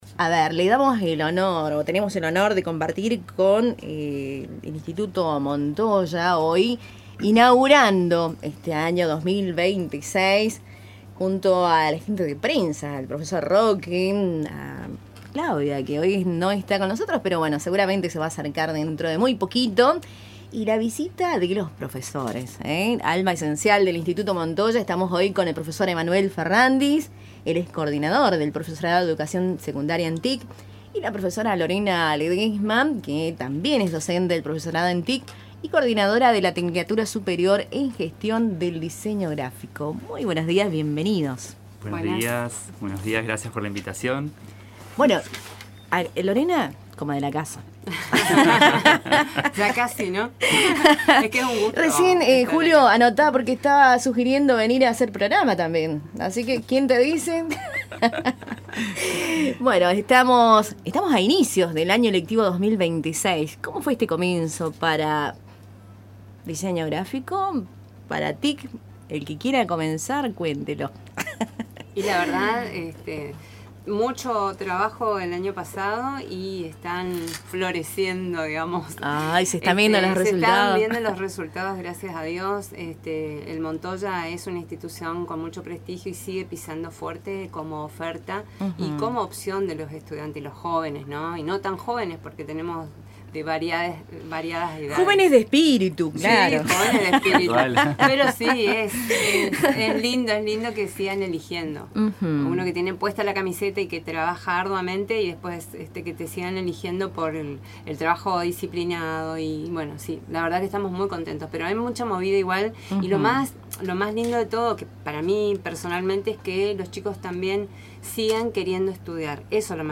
Durante una entrevista con Cultura en Diálogo